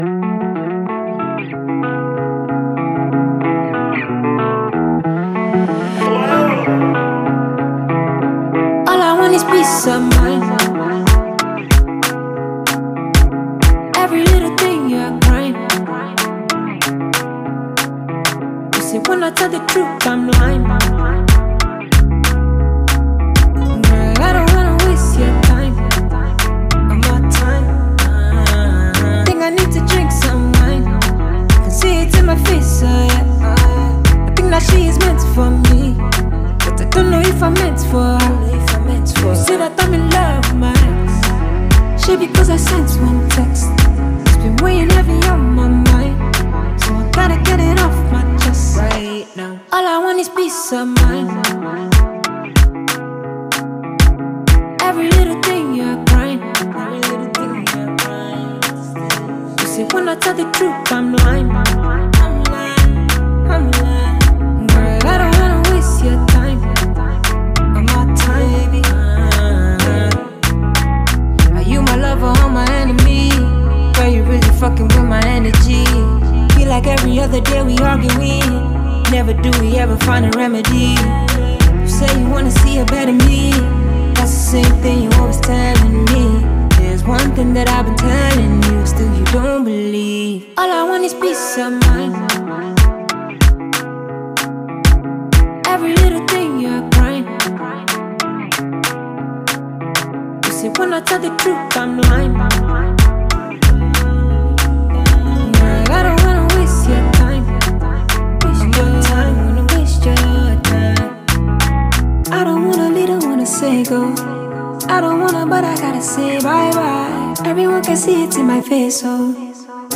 catchy number
melodious vocals